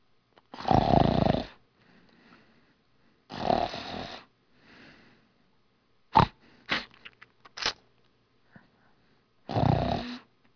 Snoring